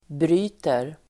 Uttal: [br'y:ter]